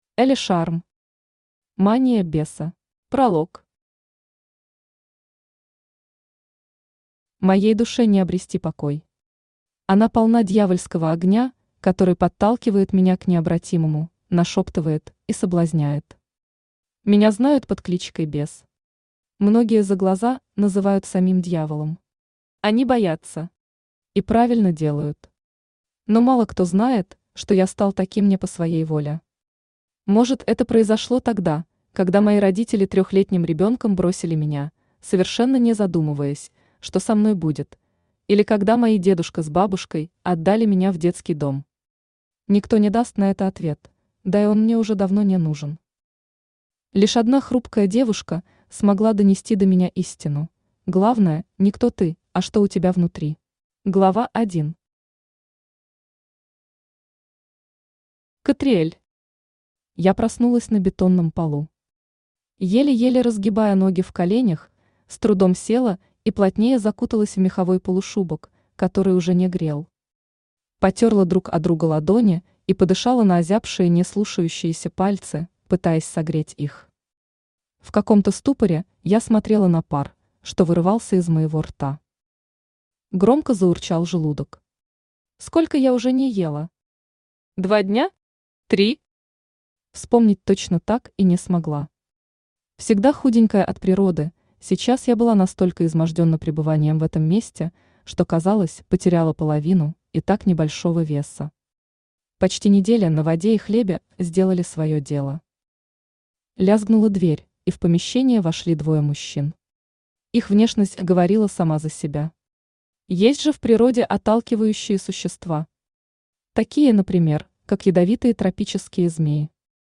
Aудиокнига Мания Беса Автор Элли Шарм Читает аудиокнигу Авточтец ЛитРес.